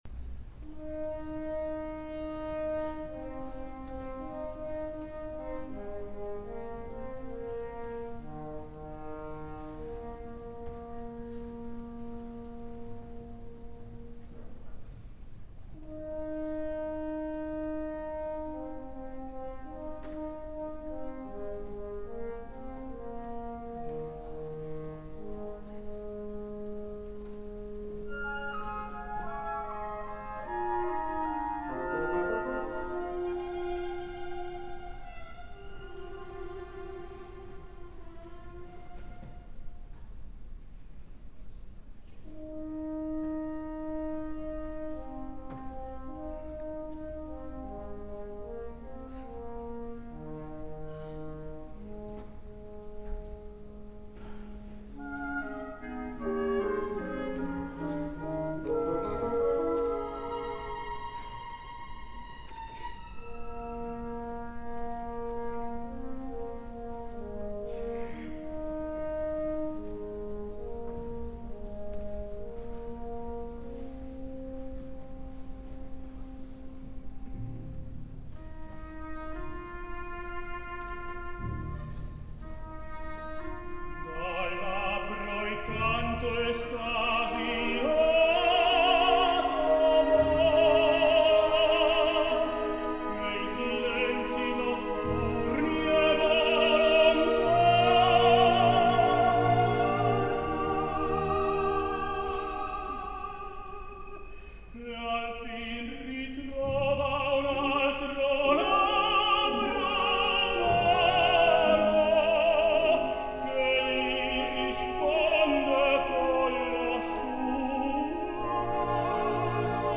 Ramon Vargas, el tenor mexicà (Mèxic DF 1960), és amb la seva fantàstica interpretació de l’ària de Fenton del Falstaff de Verdi, el cantant que heu escollit per presidir durant el mes de desembre In Fernem Land.
3 Ramon Vargas, amb Maureen O’Flynn, dirigits per Riccardo Muti 34,04%
Ara, com també succeïx al Festival de l’Eurovisió, us deixo escoltar altre cop al guanyador, Ramon Vargas, en la brillant, delicada i etèria “Dal labbro il canto”, tal i com la va cantar al juny de 1993 al Tetro alla Scala de Milà, sota la direcció del aleshores titular del teatre Riccardo Muti